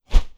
Close Combat Swing Sound 12.wav